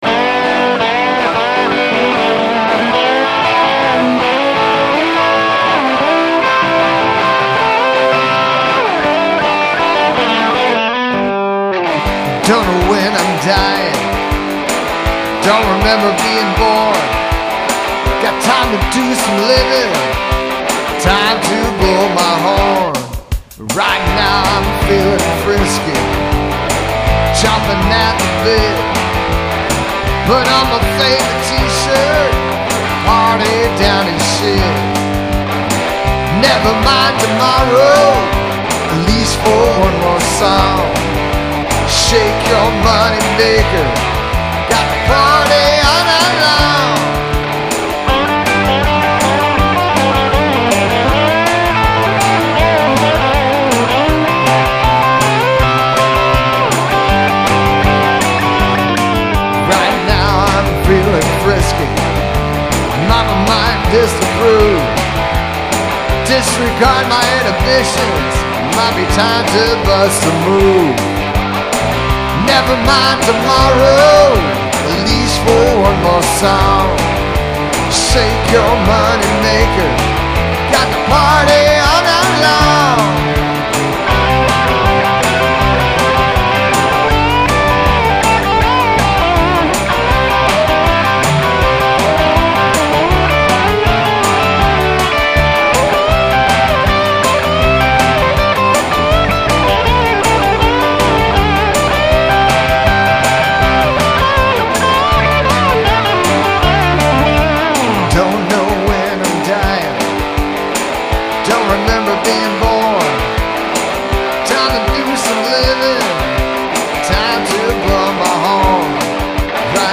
G - A - C (160 b/m) 6/16/15